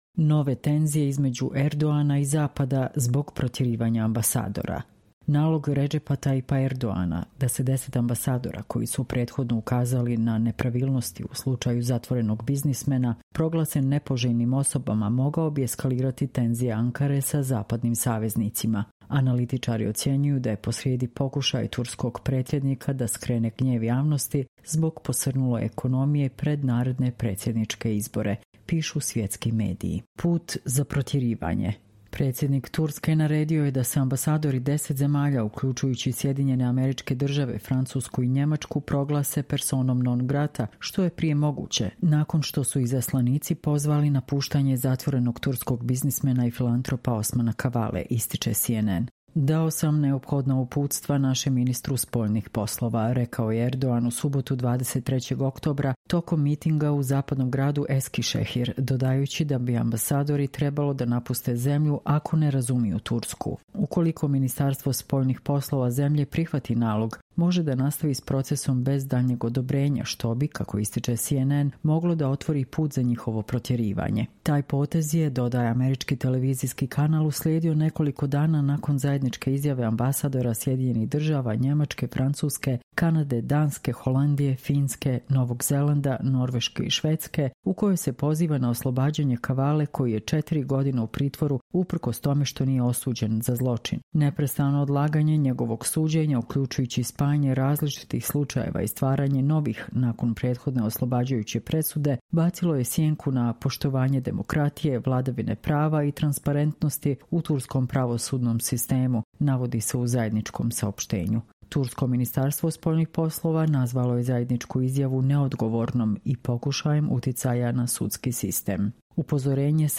Čitamo vam: Nove tenzije između Erdogana i Zapada zbog protjerivanja ambasadora